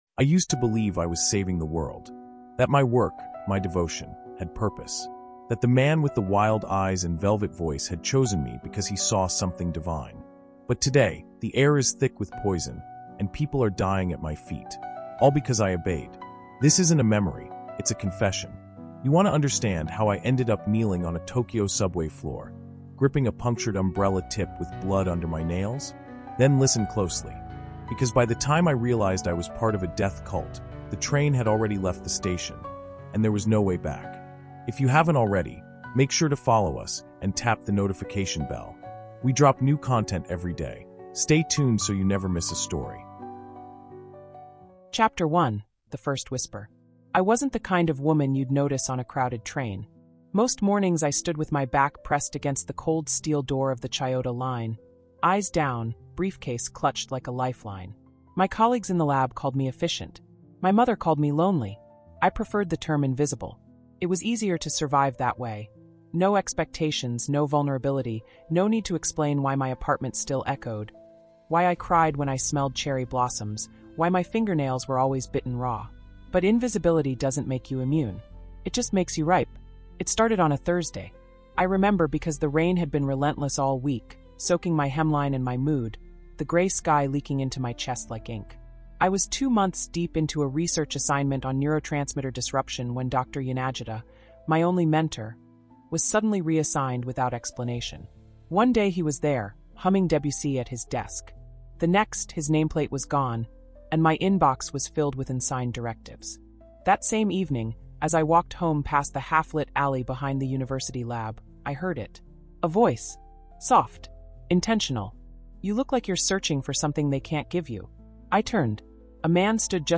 Terror in Tokyo Subway | Audiobook